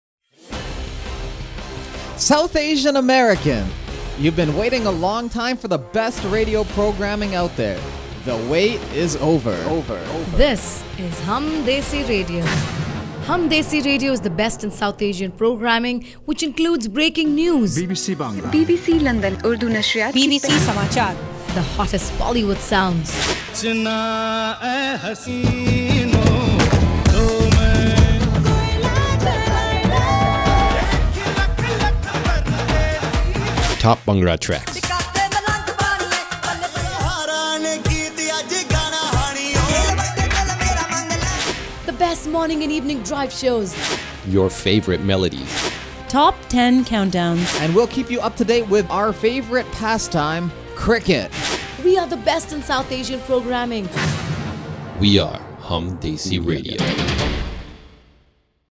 World/Variety World/Variety More Info Close